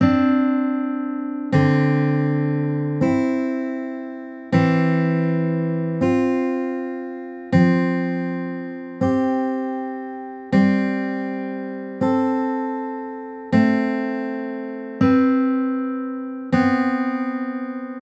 List of complementary intervals
Each measure starts with a major or perfect interval. Then the complementary interval to it. The root is always C.